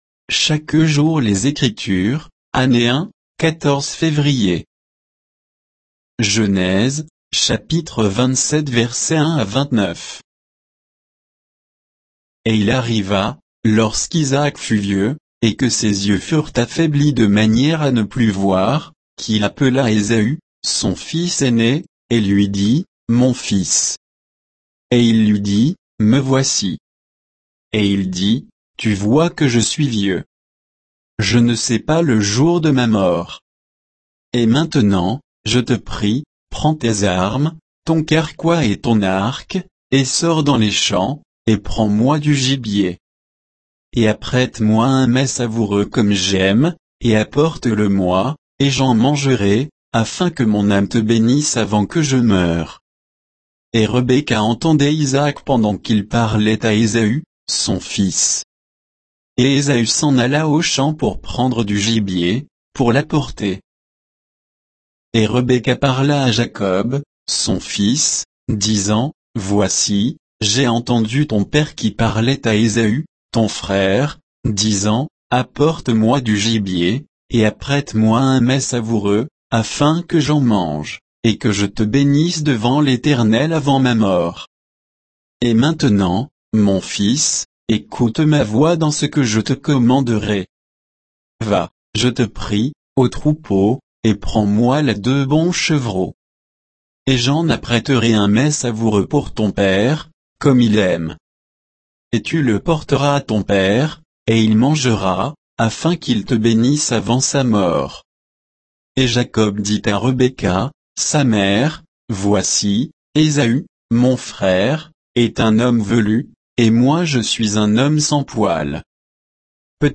Méditation quoditienne de Chaque jour les Écritures sur Genèse 27